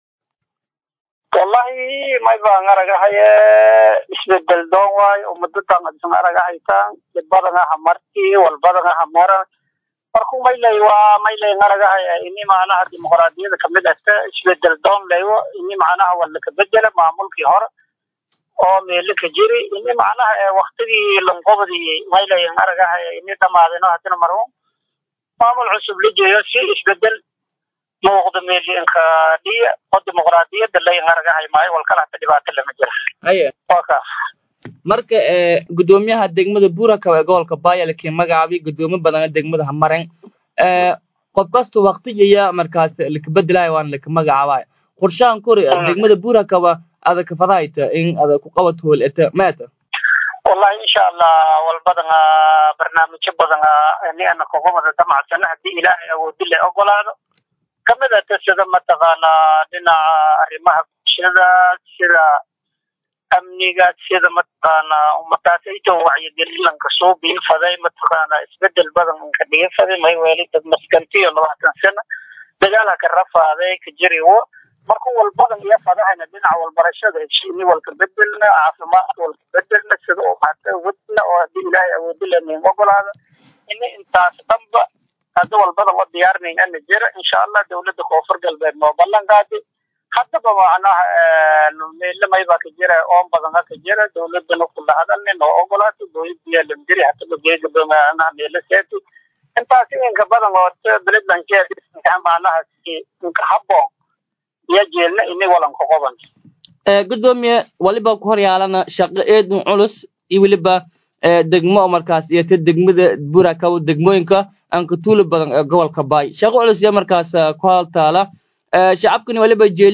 Dhageyso: Gudoomiyaha Cusub Ee Buurhakaba Oo Ka Hadley Biyo La’aan Ka Taagan Degmadaas